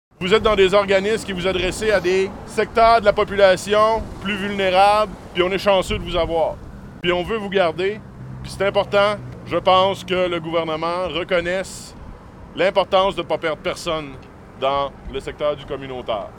Le maire Antonin Valiquette a aussi pris la parole pour s’adresser aux organismes présents: